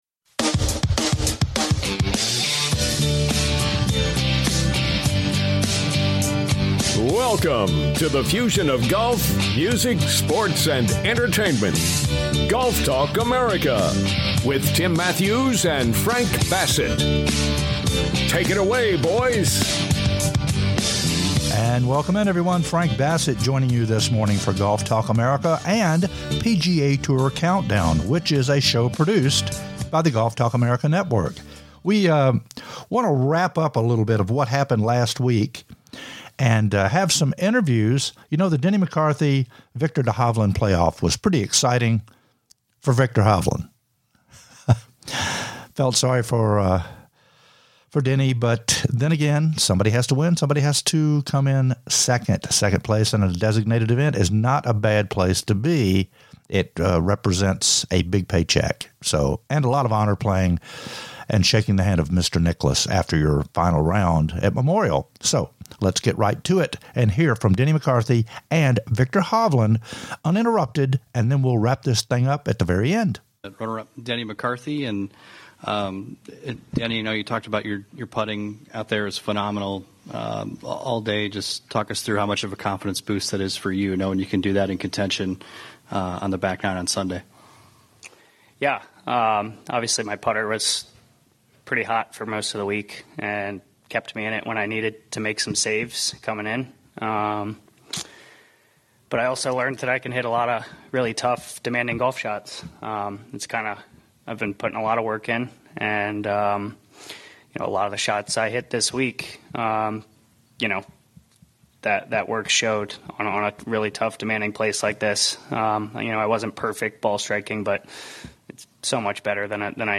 Live interviews from the winner & runner up, Hovland & McCarthy plus words from The GOAT, Jack Nicklaus.....